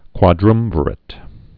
(kwŏ-drŭmvər-ĭt)